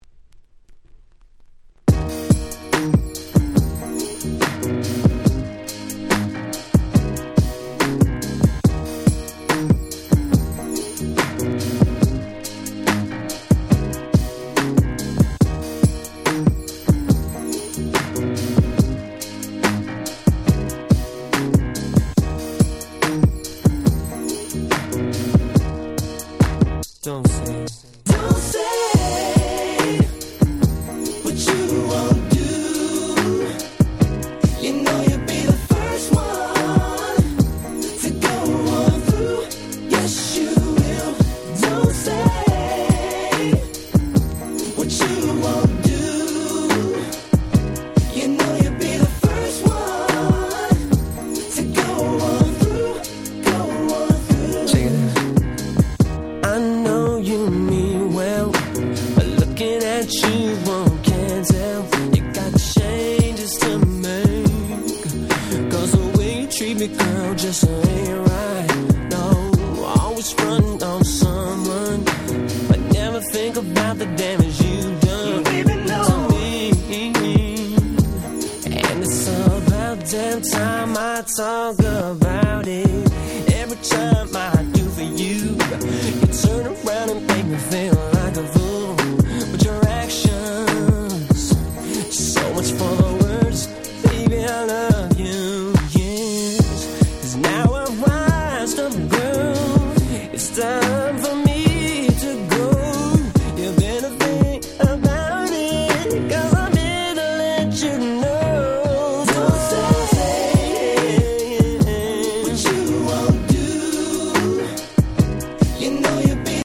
97' Smash Hit R&B / Slow Jam !!
まったりとしたNeo Soulで本当に最高。